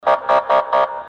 Сирена
гудки